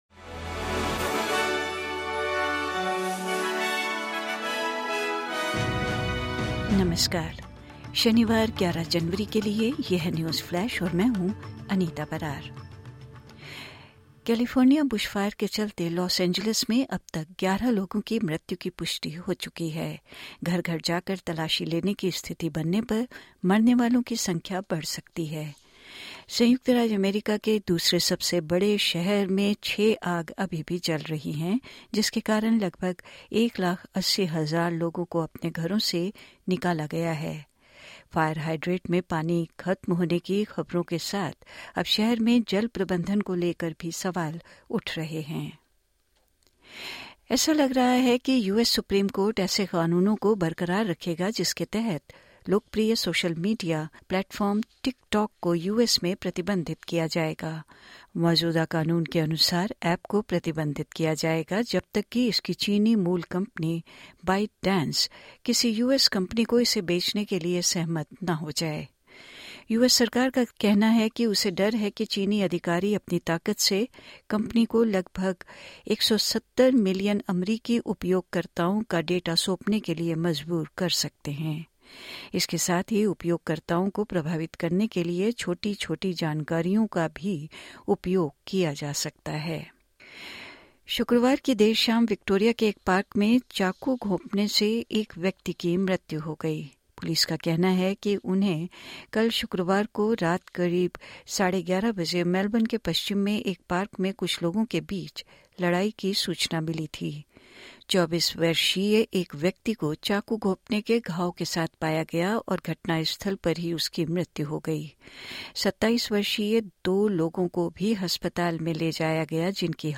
सुनें 11/01/2025 की प्रमुख खबरें ऑस्ट्रेलिया और भारत से हिन्दी में।